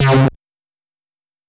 808s
Acid Bass (On Sight).wav